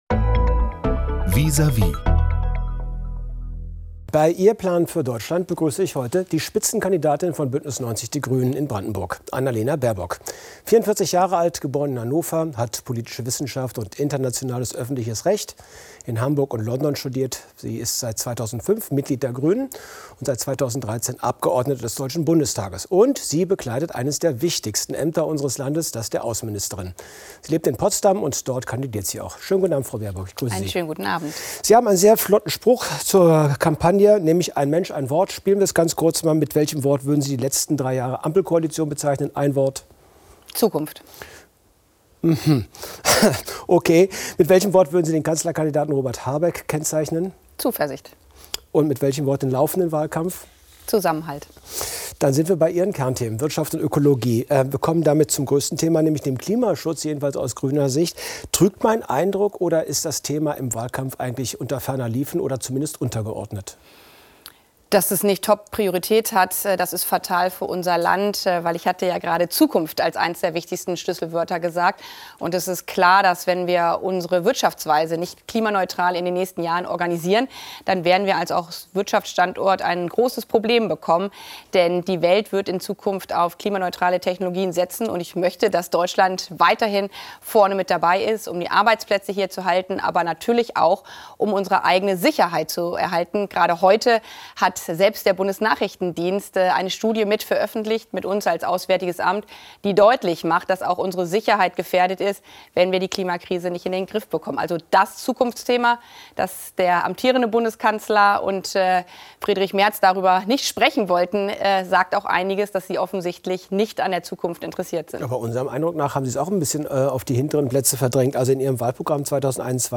Hinweis: Das Interview wurde vor dem Gespräch zwischen Donald Trump und Wladimir Putin zur Ukraine aufgezeichnet.